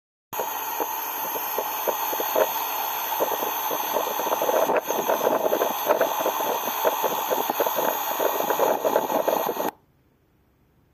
Concernant l'UE : le ventilateur tourne normalement mais le compresseur grésille en continu mais ne démarre pas.
J'ai procédé à un enregistrement du bruit.
bruit-compresseur-clim.mp3